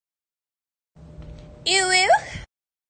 Uwu Discord Girl